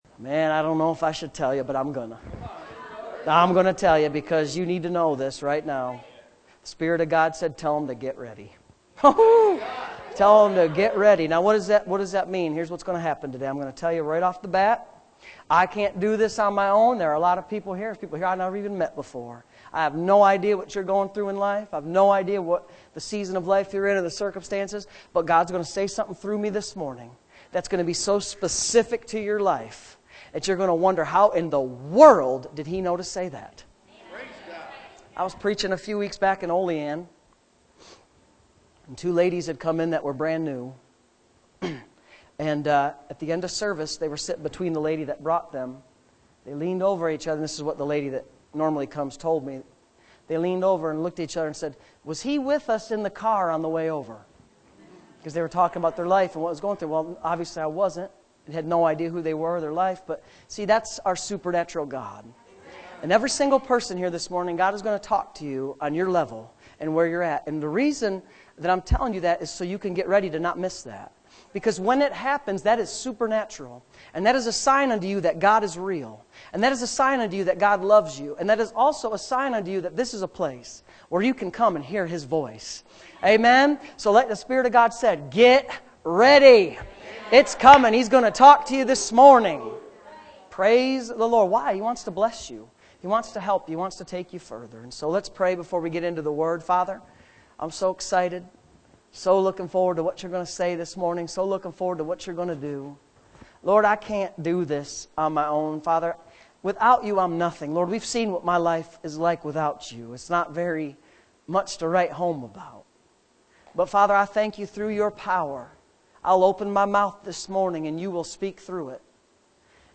Bearing Much Fruit Tagged with Sunday Morning Services